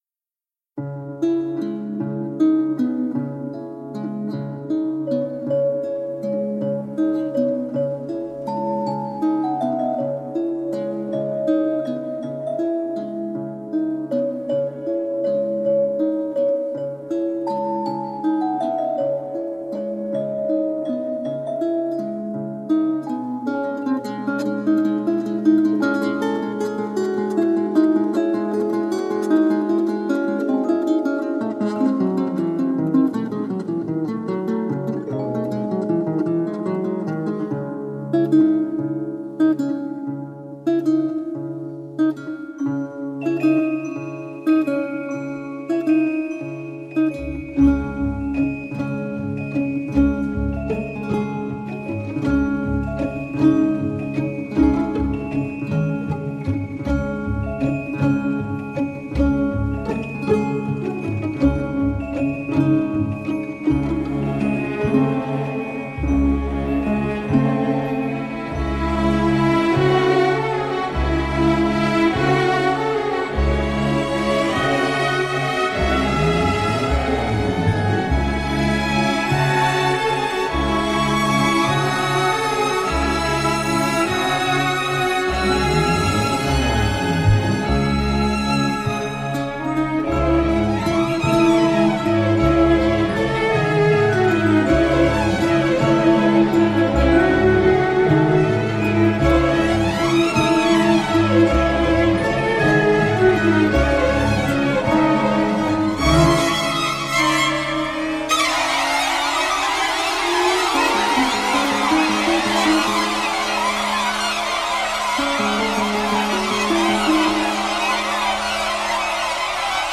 Expérimental, étrange, parfois anxiogène